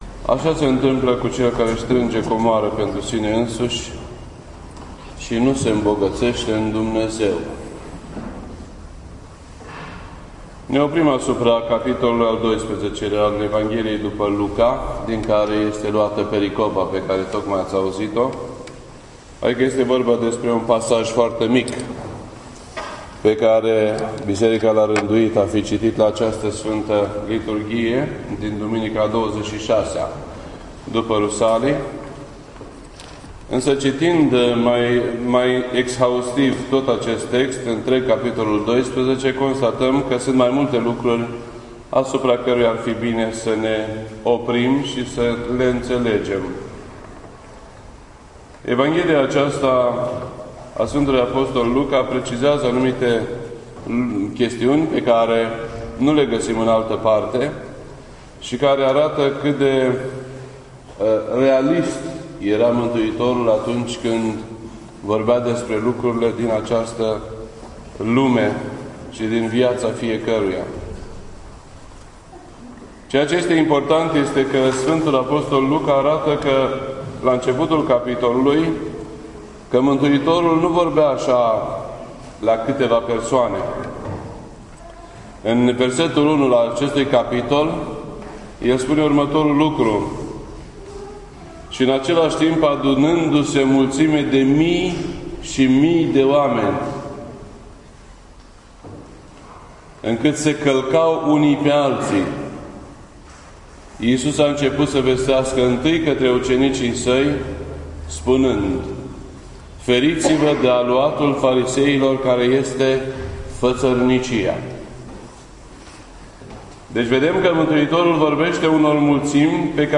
This entry was posted on Sunday, November 22nd, 2015 at 11:37 AM and is filed under Predici ortodoxe in format audio.